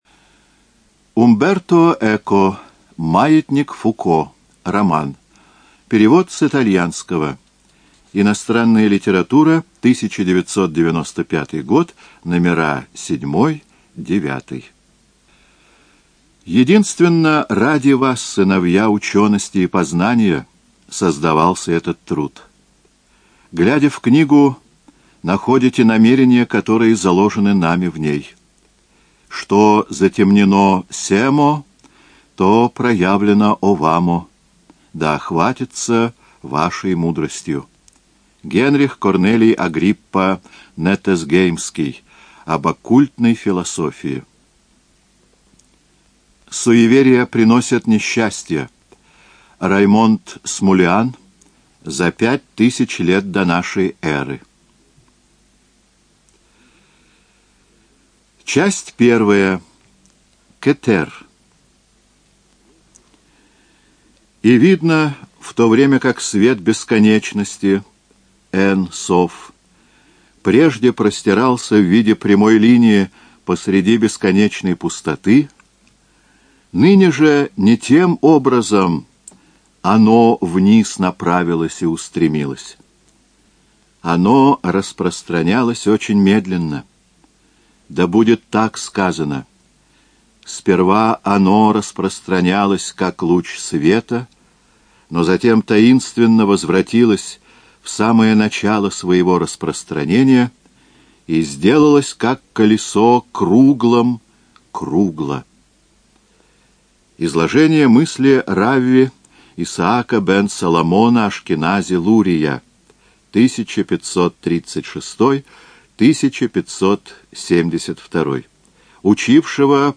ЖанрСовременная проза
Студия звукозаписиЛогосвос